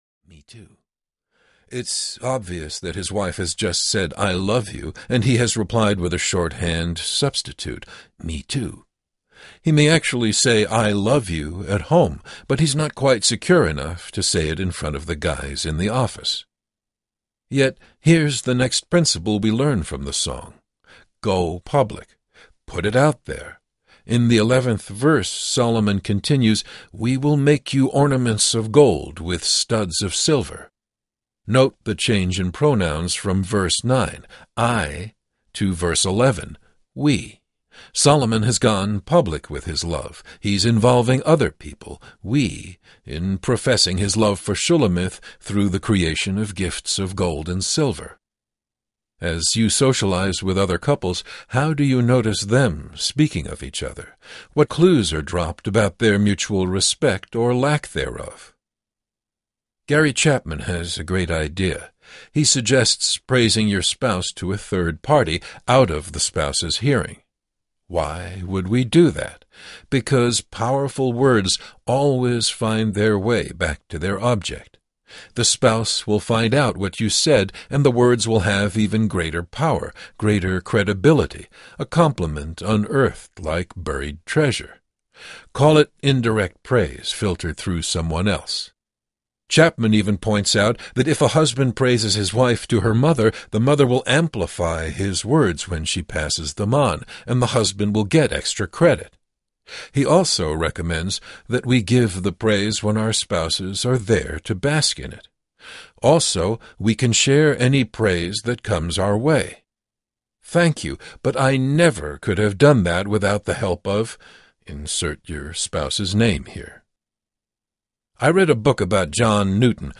What the Bible Says about Love Marriage and Sex Audiobook
Narrator
10 Hrs. – Unabridged